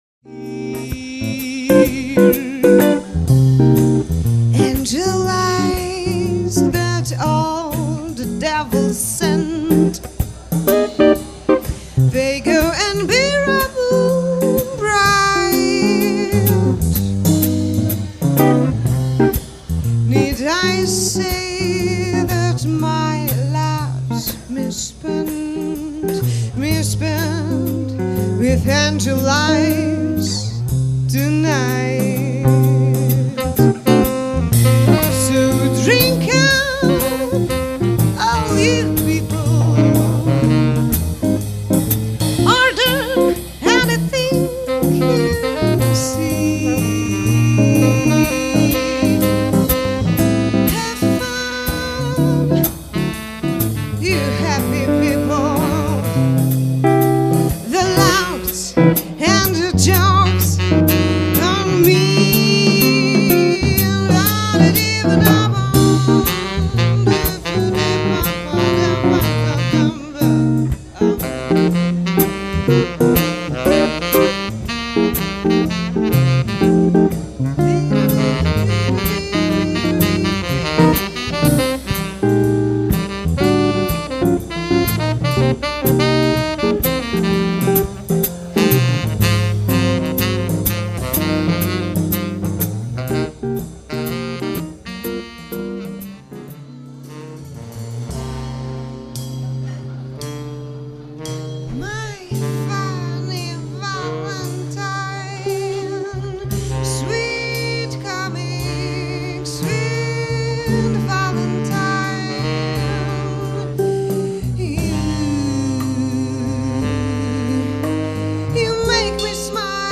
Występ jest wyrazem hołdu składanego wybitnym twórcom, będąc jednocześnie okazją by posłuchać dobrego jazzu granego na żywo.
Pojawią się ballady jazzowe, swing, bossa nova i be bop.
perkusista